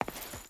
Stone Chain Walk 3.ogg